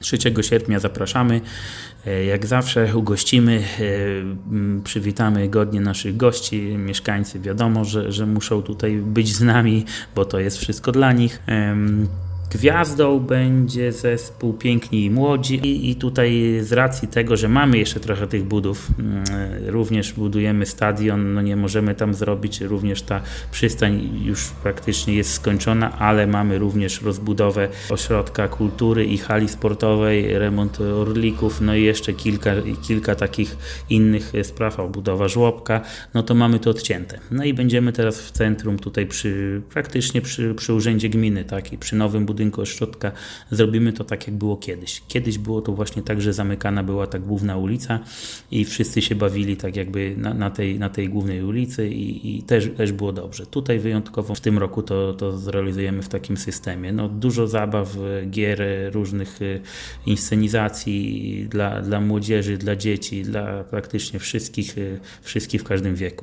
Na Dzień Gminy Giby zaprasza Robert Bagiński, wójt gminy.